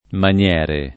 vai all'elenco alfabetico delle voci ingrandisci il carattere 100% rimpicciolisci il carattere stampa invia tramite posta elettronica codividi su Facebook maniero [ man L$ ro ] (antiq. maniere [ man L$ re ]) s. m. (lett.